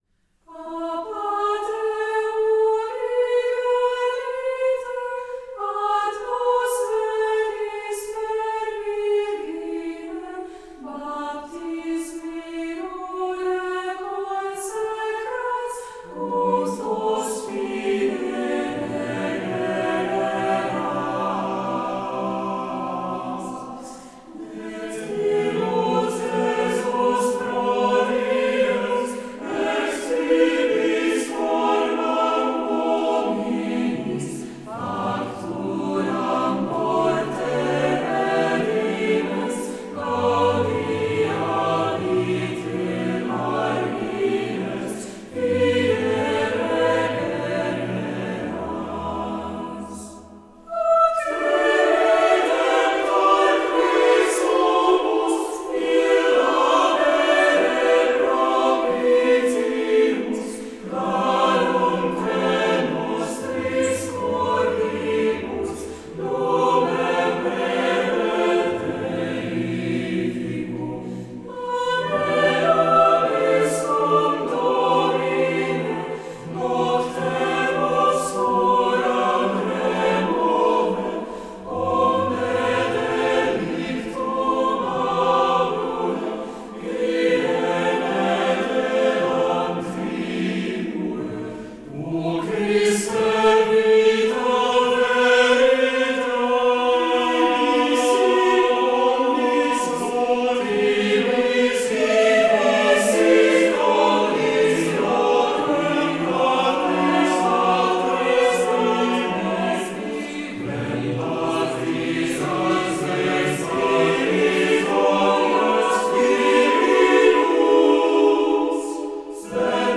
Mixed Choir Tempo - Medium Fast BPM - 97